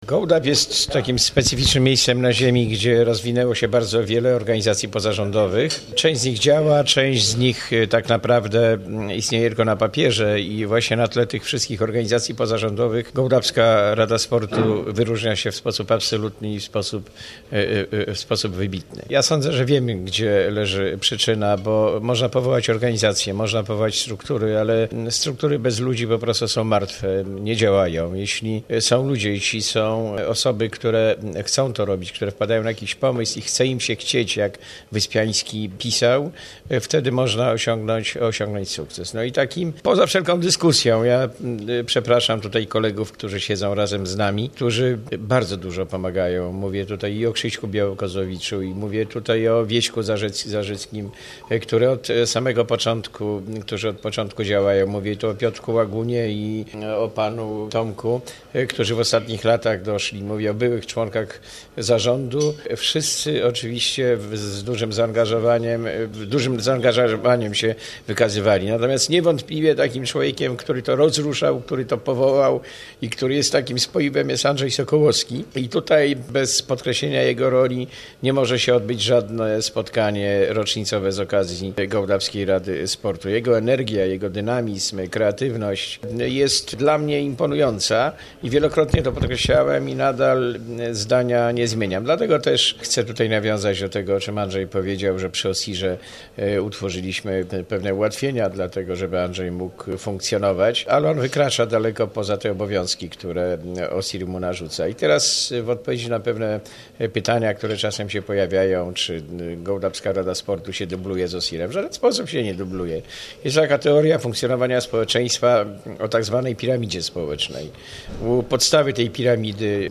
W spotkaniu w Restauracji „Matrioszka" wziął udział zarząd organizacji, a także burmistrz Marek Miros, dawniej także członek zarządu.
mówi Marek Miros